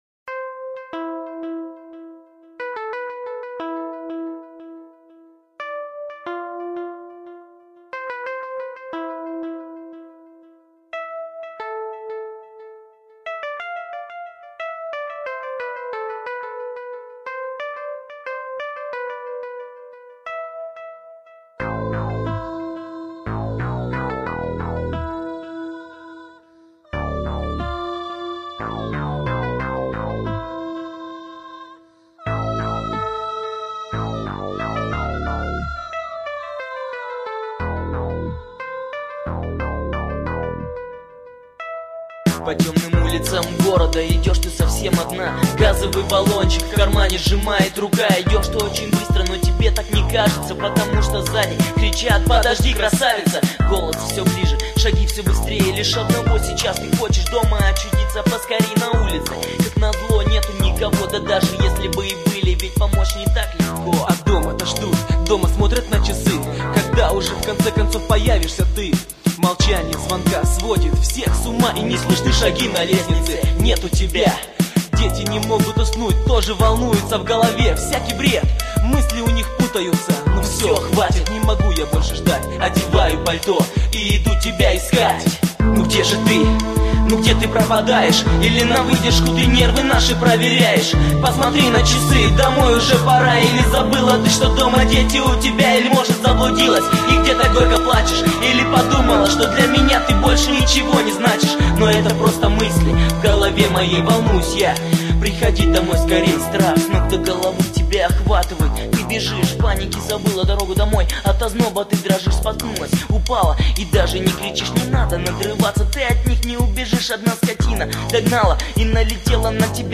Грустная песня